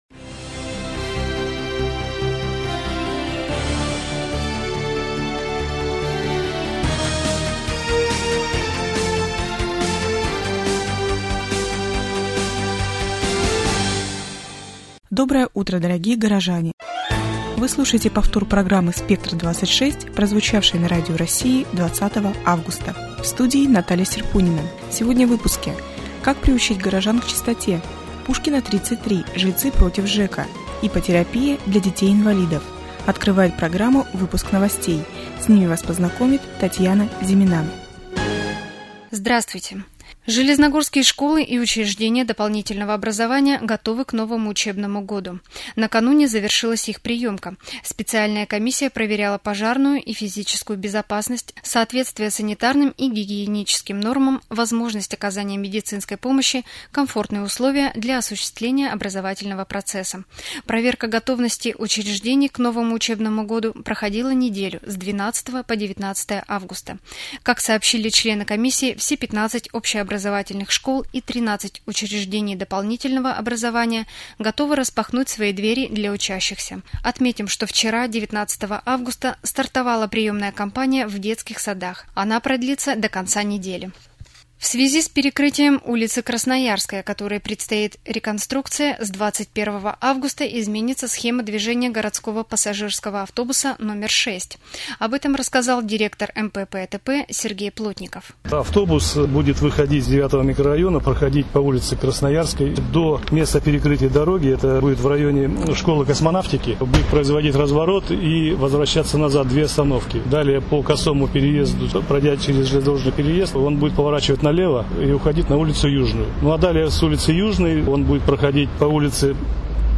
РАДИОПРОГРАММА ЗА 20 АВГУСТА » Свежее телевидение - Железногорск